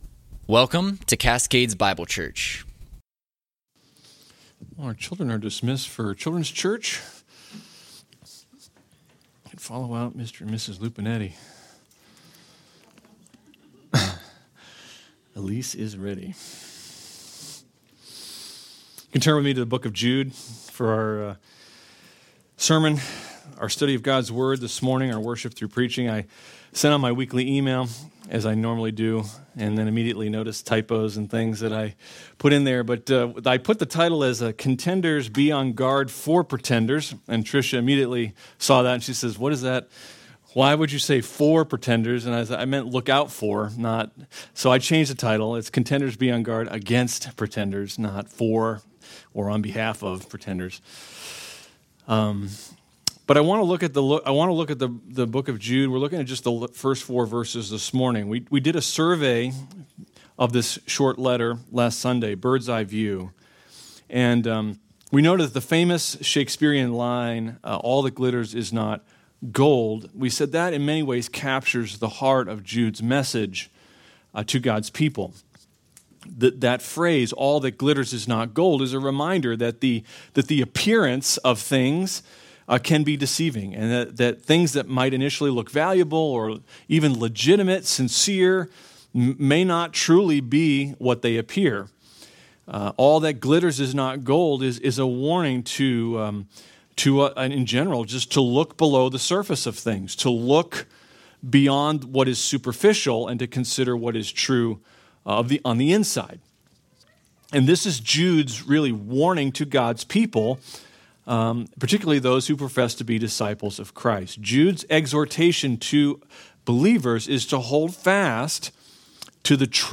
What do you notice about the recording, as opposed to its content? Sermons from Cascades Bible Church: Sterling VA